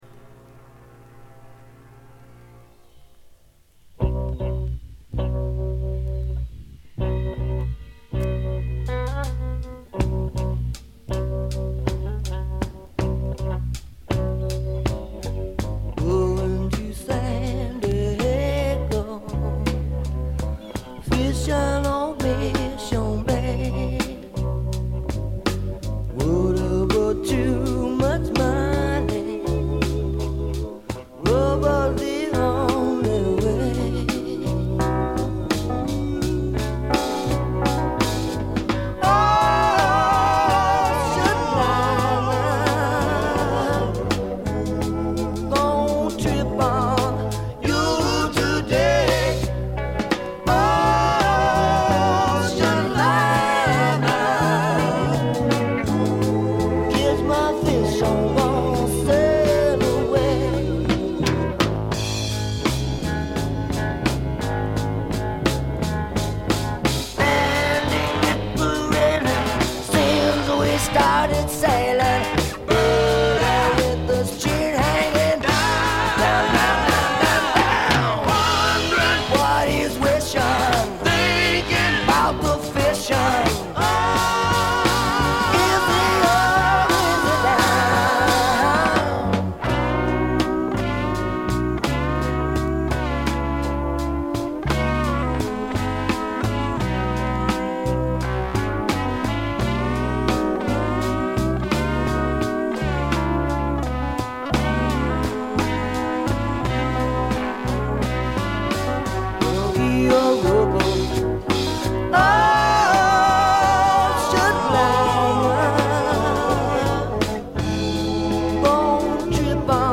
部分試聴ですが、軽微なノイズ感のみ。
試聴曲は現品からの取り込み音源です。